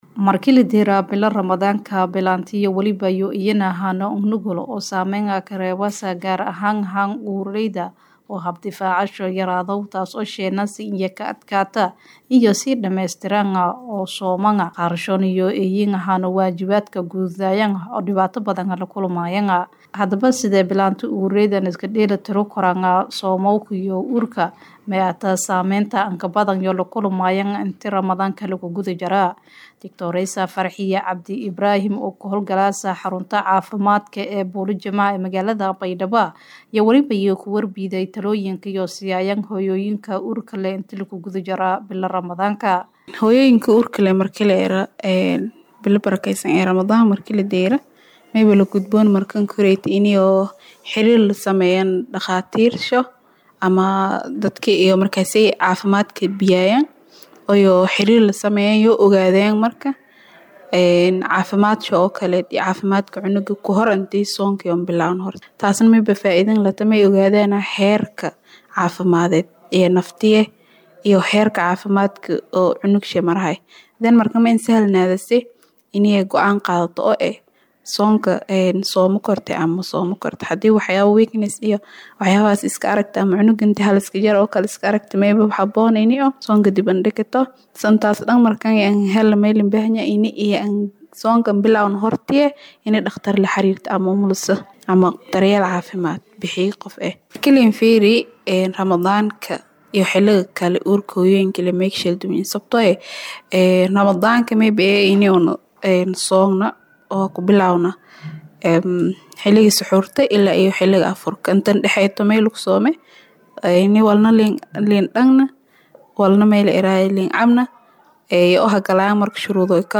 Warbixin ku Saabsan Hooyada Uurka leh iyo Soonka